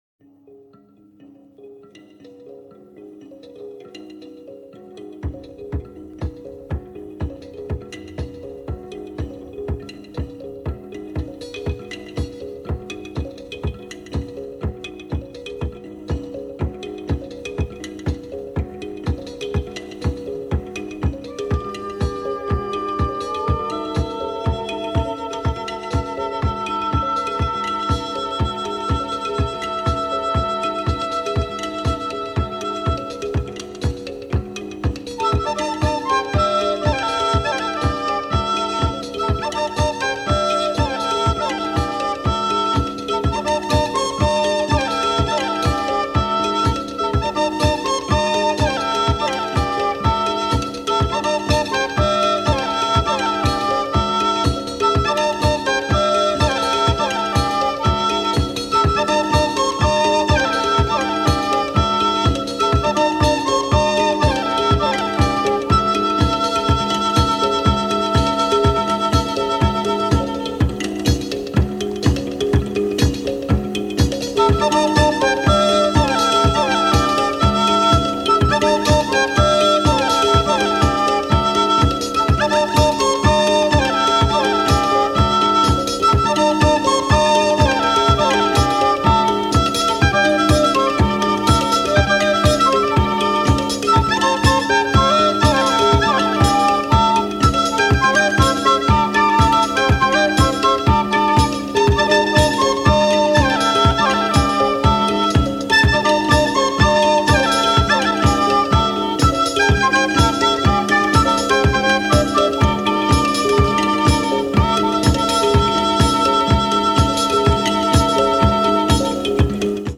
親指ピアノの響きを活かした、スピリチュアルでミニマルな極上アフロ・サイケ！
サンザの深い響きに誘発されたのか、益々スピリチュアルでミニマルな要素が強く感じられますね！